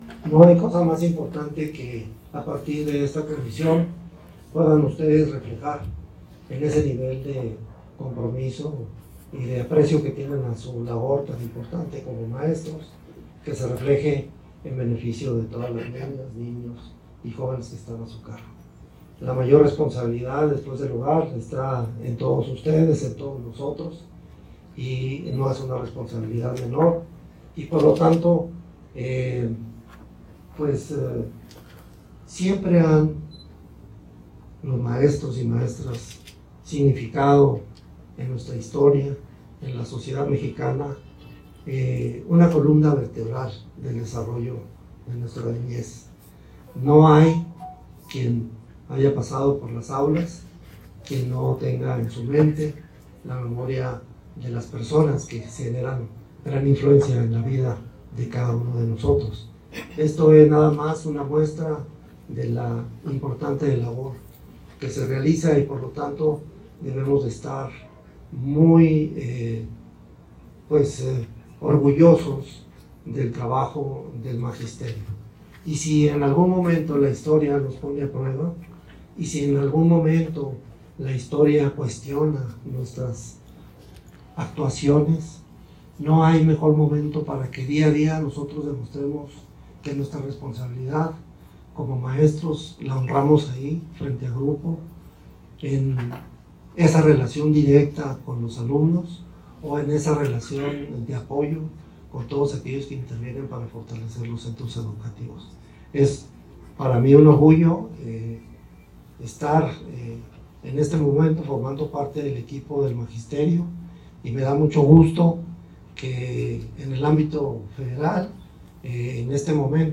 audio_secretario_de_educacion_y_deporte_reunion_seech.mp3